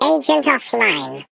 Sfx_tool_spypenguin_vo_exit_04.ogg